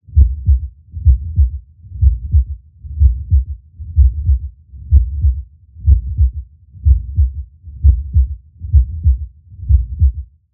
心音のクイズ問題2.mp3